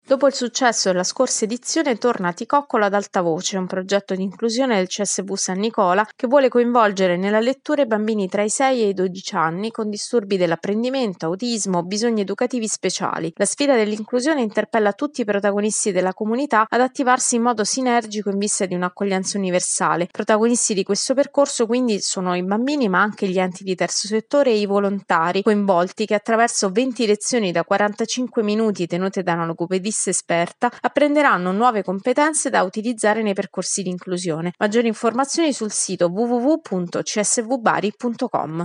servizio-csv-bari.mp3